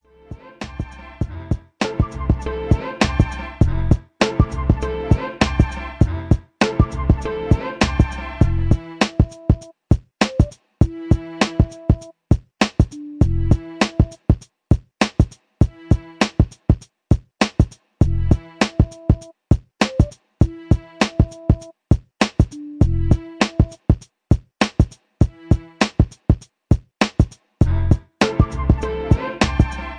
Abstract Hip Hop Beat